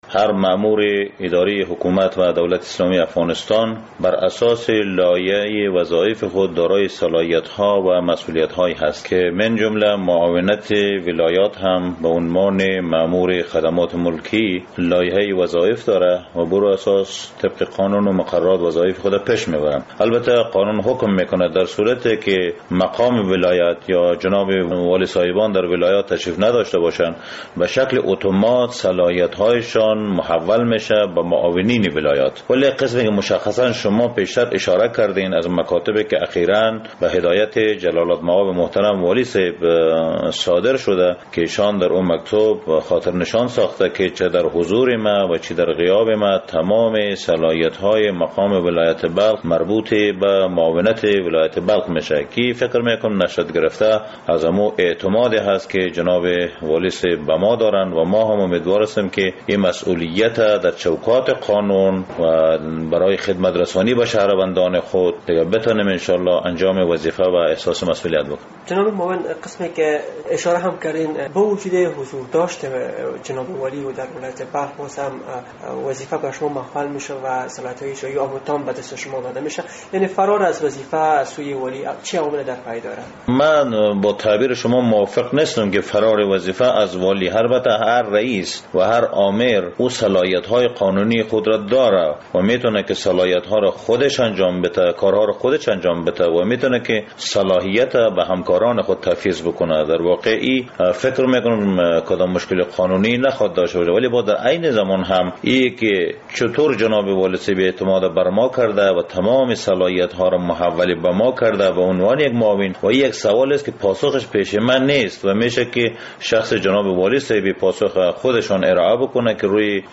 مصاحبه: والی بلخ عطا محمد نور، صلاحیت های اجراییوی خود را به معاون خود تفویض کرد